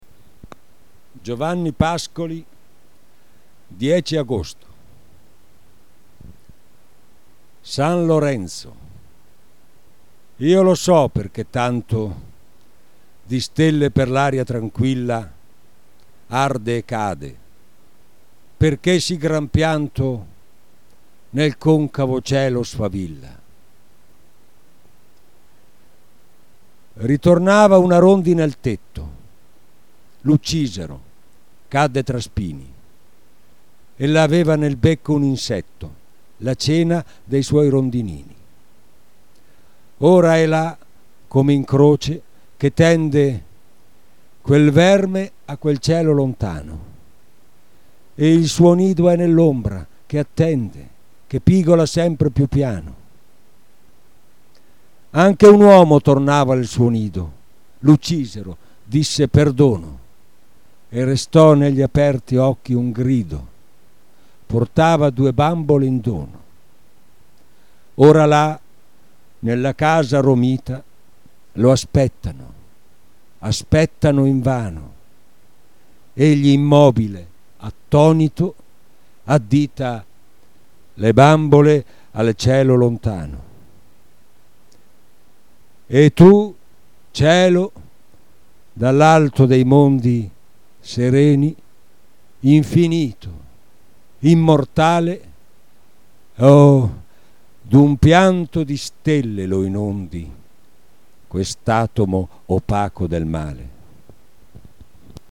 Suoni poetici » Poesie recitate da artisti
attore e regista